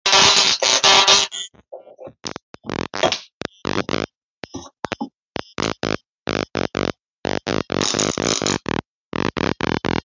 Lightning + Potato + Lemon Sound Effects Free Download